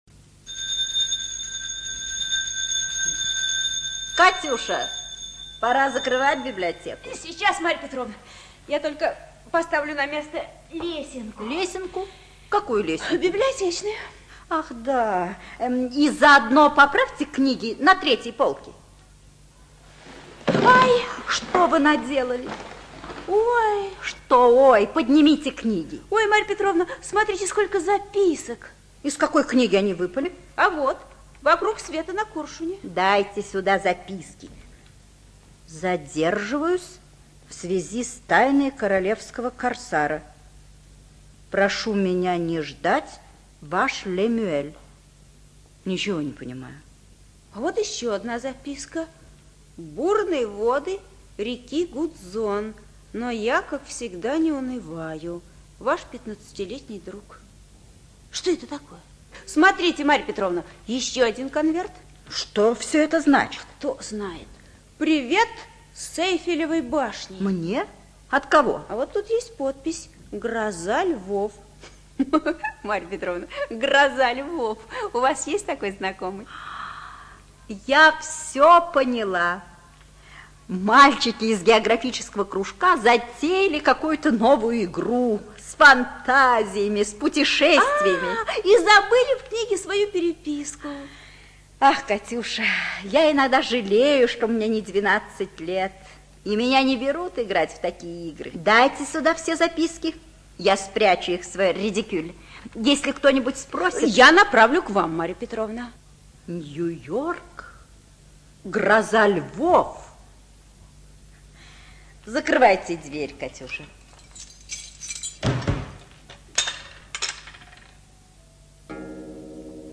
ЖанрДетская литература, Путешествия, Радиопрограммы